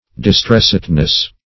Search Result for " distressedness" : The Collaborative International Dictionary of English v.0.48: Distressedness \Dis*tress"ed*ness\, n. A state of being distressed or greatly pained.
distressedness.mp3